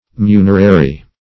Meaning of munerary. munerary synonyms, pronunciation, spelling and more from Free Dictionary.
Search Result for " munerary" : The Collaborative International Dictionary of English v.0.48: Munerary \Mu"ner*a*ry\, a. [L. munerarius, from munus a gift.]